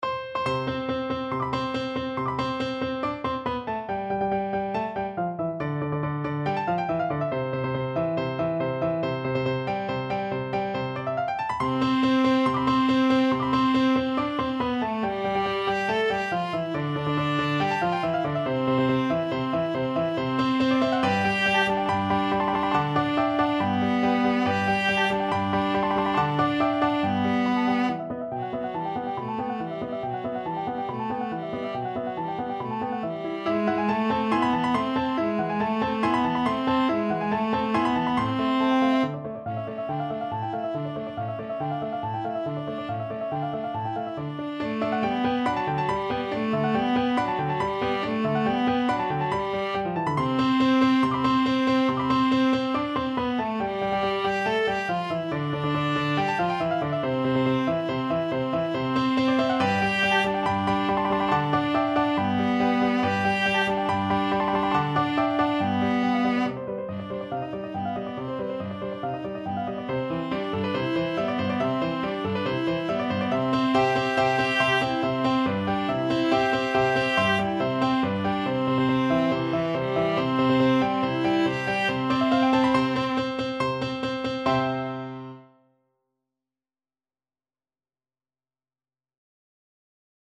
2/4 (View more 2/4 Music)
~ = 140 Allegro vivace (View more music marked Allegro)
Classical (View more Classical Viola Music)